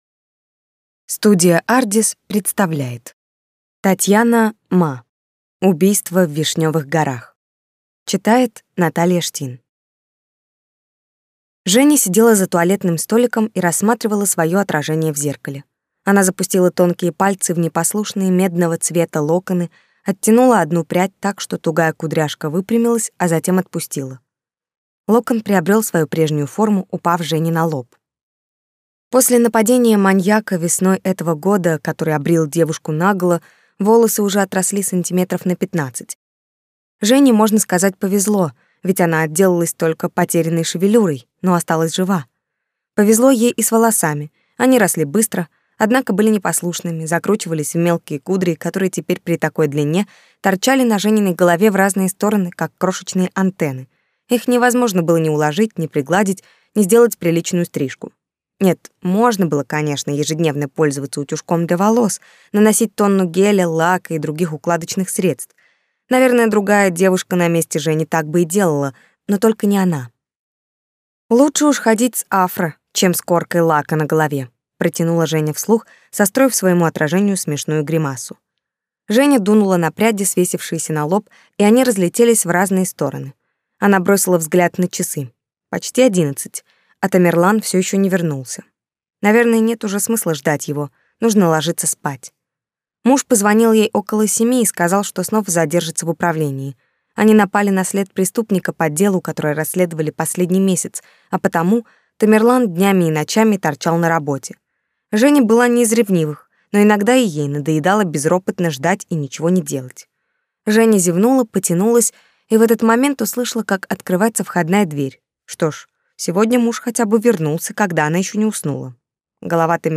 Аудиокнига Убийство в Вишнёвых горах | Библиотека аудиокниг